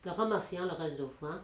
Collectif - ambiance
Catégorie Locution